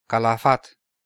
Calafat (Romanian pronunciation: [kalaˈfat]